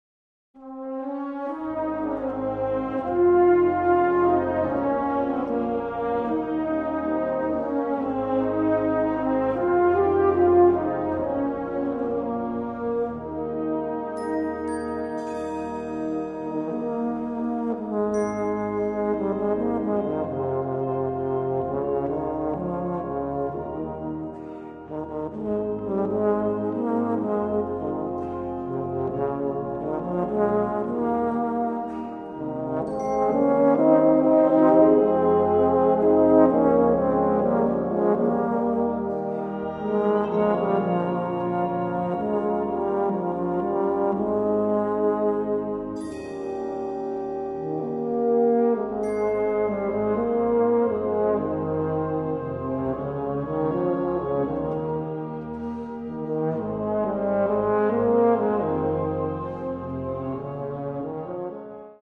prachtige ballade